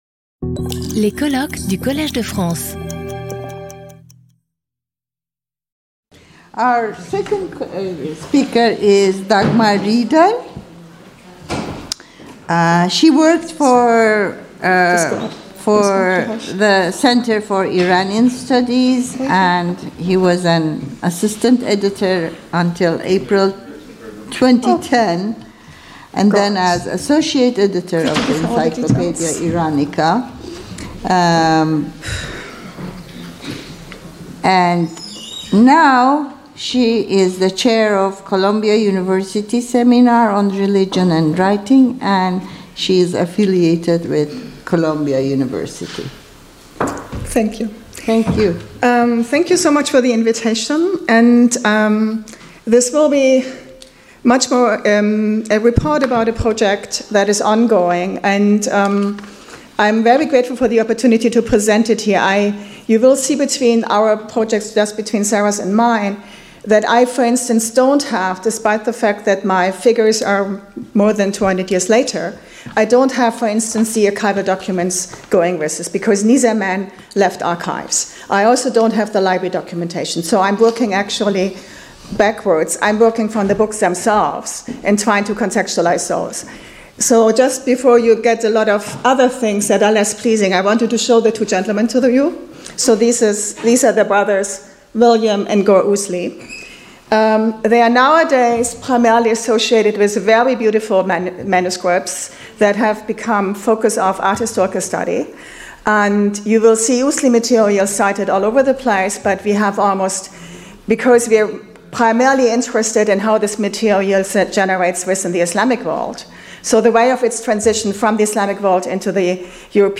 Lecture audio